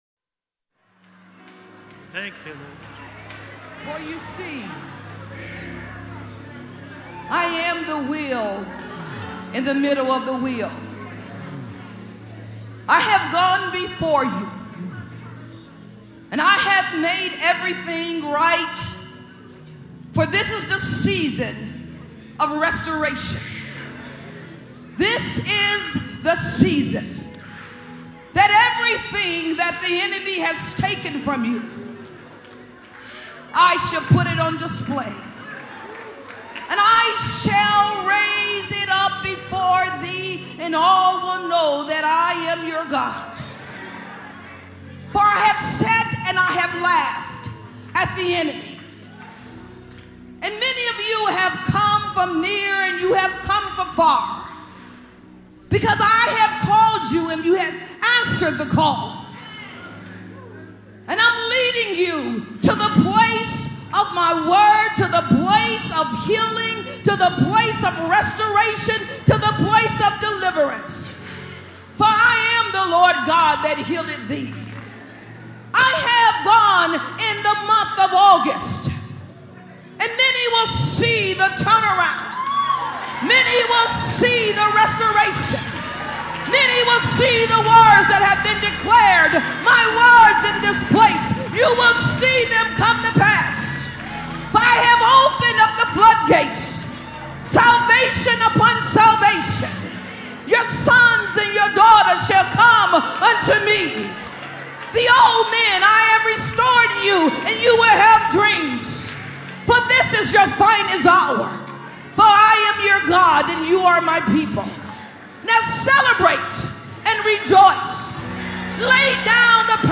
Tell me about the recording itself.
Receive the Word of the Lord for your life with these four on time prophetic words from 2018 released during Living Word Christian Center services. You will be empowered and encouraged to move forward into your full potential and destiny.